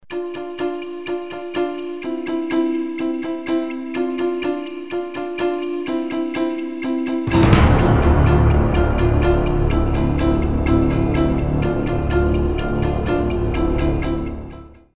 Documentary 06b